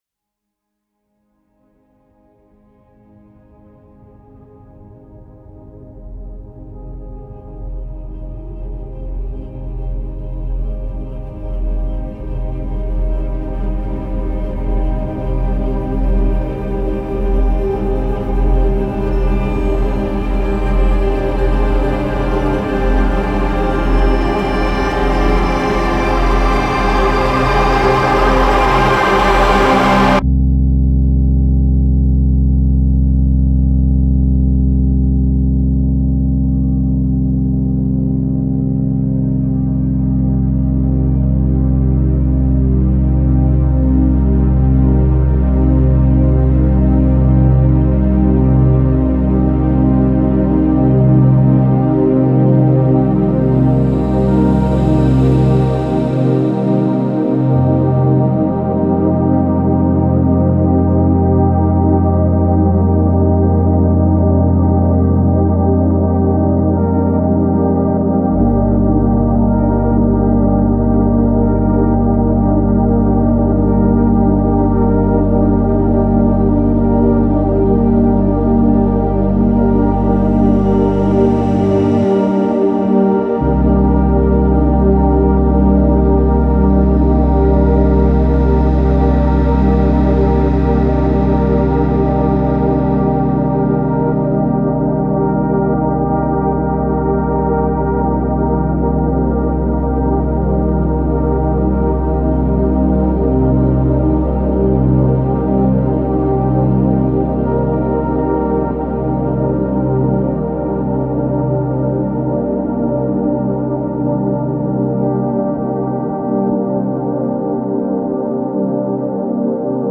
Dark Cinematic Drama